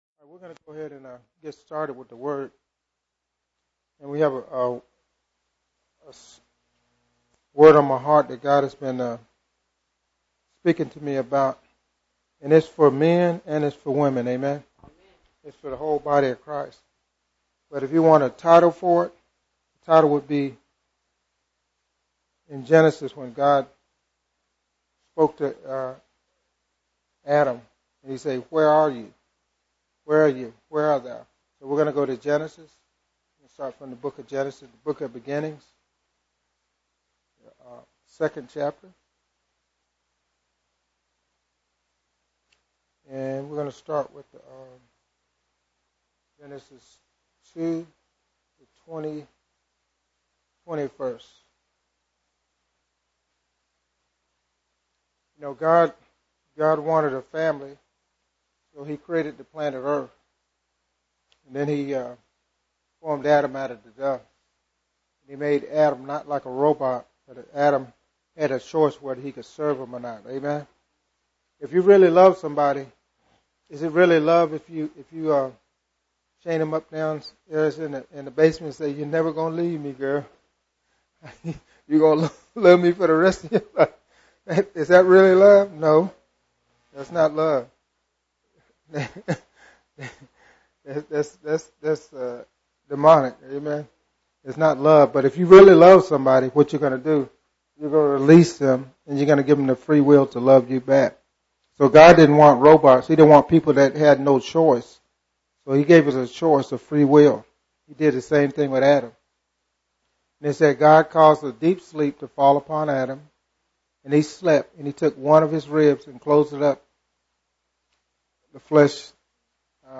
Genre: Sermons.